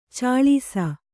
♪ cāḷīsa